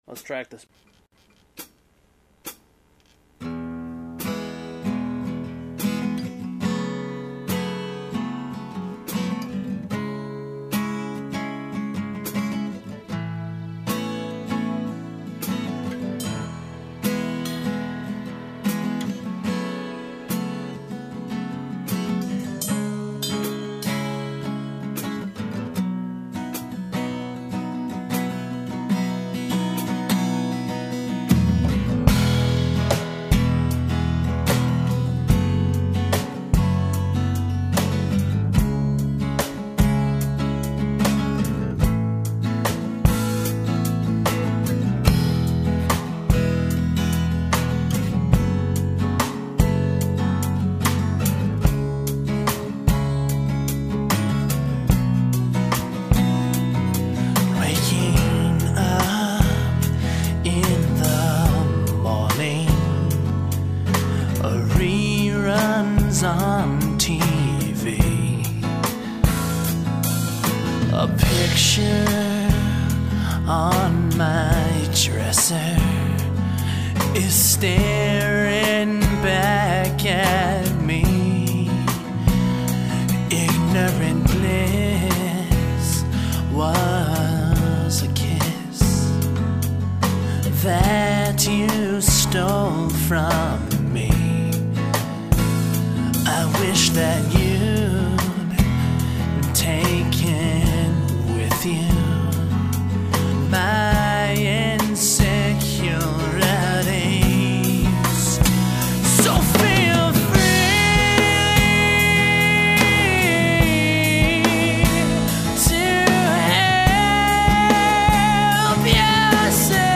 electric guitar, bass guitar, drum kit, & keys